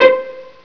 PLUCK_A.WAV